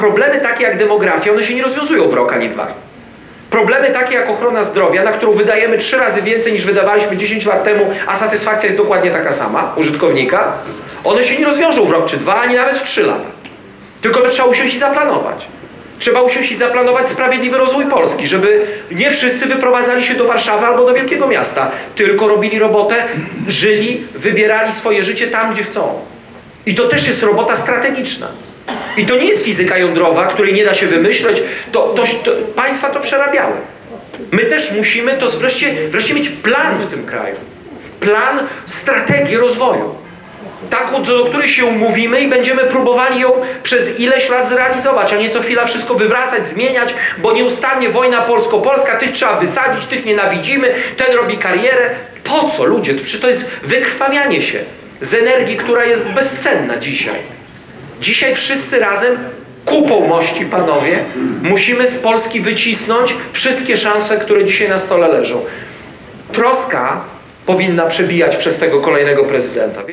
Spotkanie odbyło się w Parku Naukowo-Technologicznym Polska-Wschód.